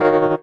17RHODS01 -L.wav